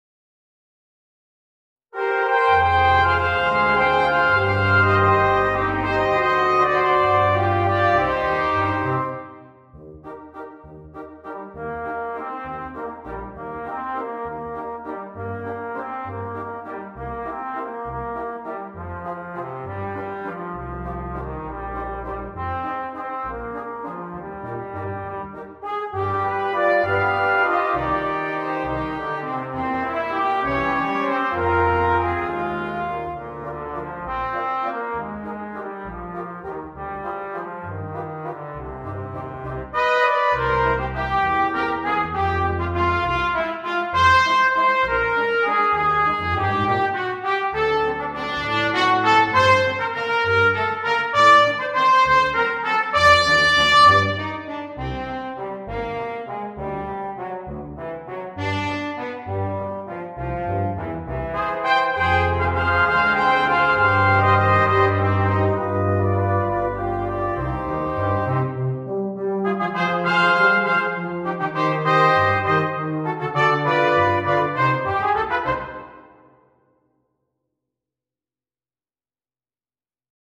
для брасс-квинтета.